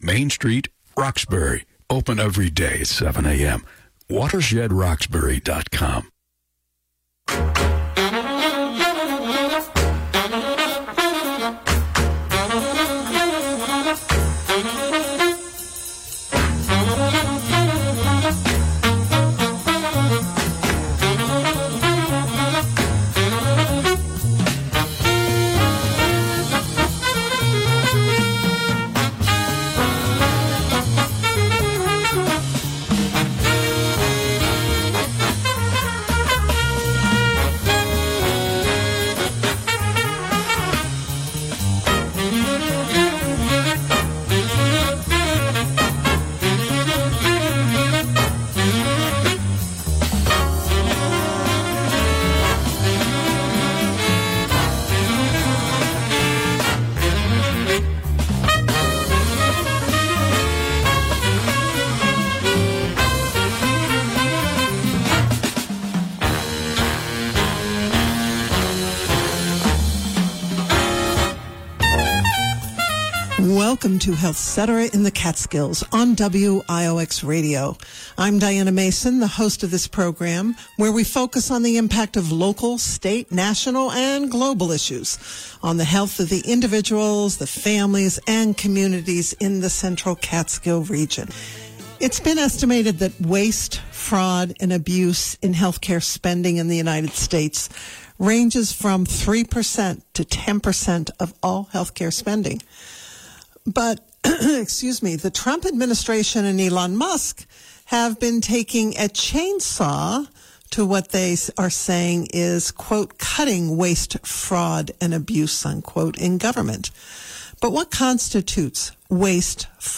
This interview first aired on HealthCetera in the Catskills on WIOX Radio on May 21, 2025.